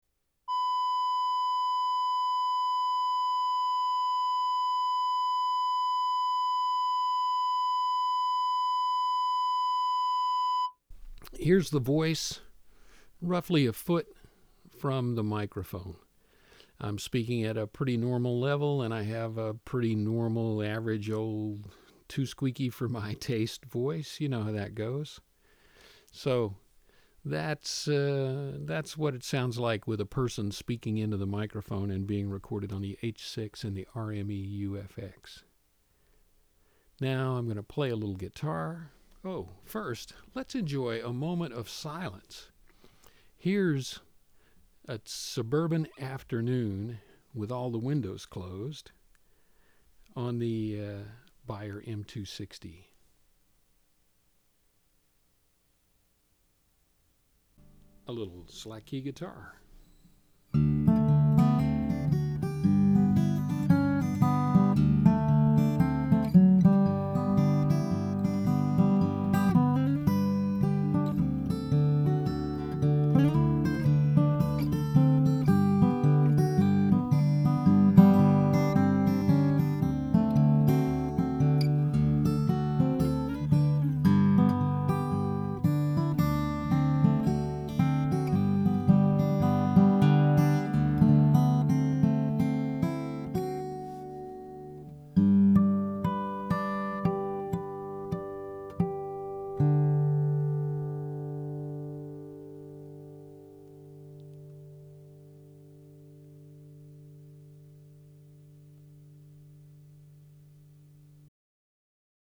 As in the previous comparison I connected the Beyer M260 with its ENAK re-ribbon to my Coleman LS1 passive switcher/splitter.
I hooked up my Oscar Sound Tech headset mic to a Zoom H1 to capture the overall narration, shot a video using the Sanyo Xacti HD2000, and played a bit of slack key on the Wingert Model E.
I recorded the RME in REAPER, then pulled the Zoom H6 file into REAPER as well.
Here are the resulting audio tracks, which include a bit of the test tone, some narration, room tone, and solo acoustic guitar. They are mono 44.1 khz 16 bit PCM so they will not stream well.
UFX Ribbon Sample